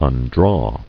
[un·draw]